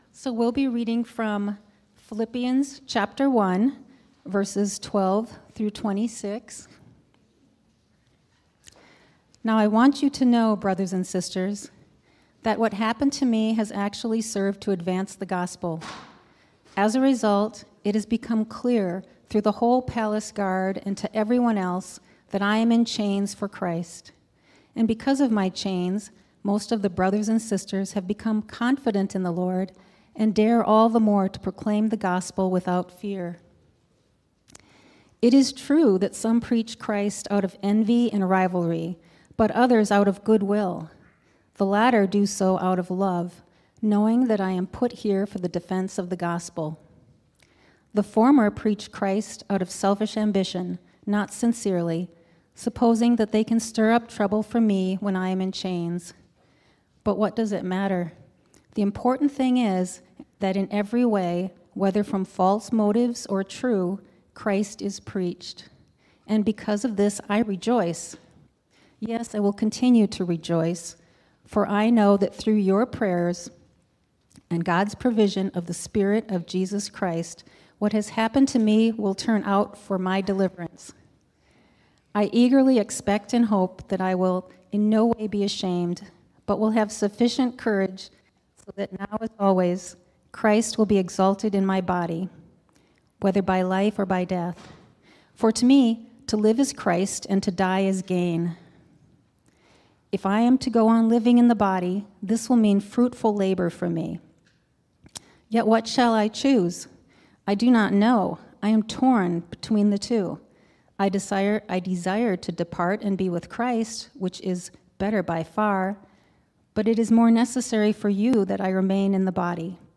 sermon-1-19-25.mp3